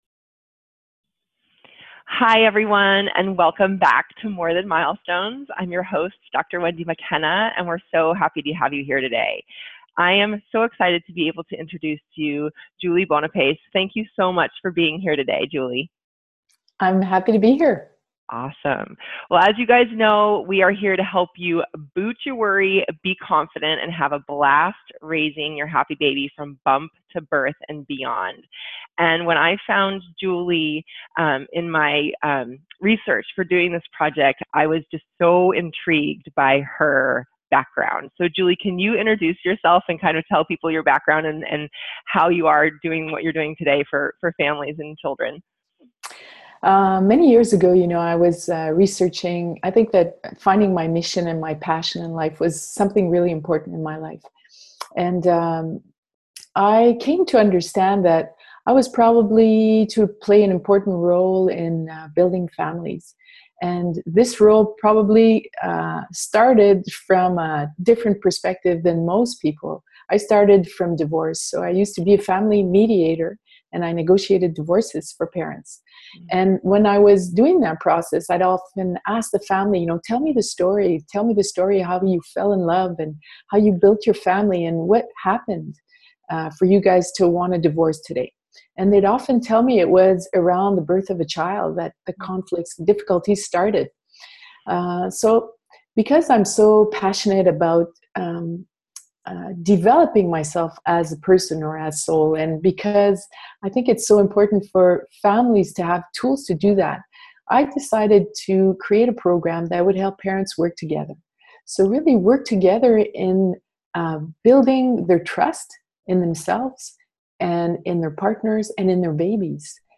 During our interview, here is what we discussed and shared: Many conflicts between our partners and us start around the birth of a child Even though we are hardwired for birth and parenting, we feel that others know better and often give away our power We usually put our attention and money into the wrong thing, investing in things that are less important than creating and maintaining a healthy, happy and thriving family Click here to access all speakers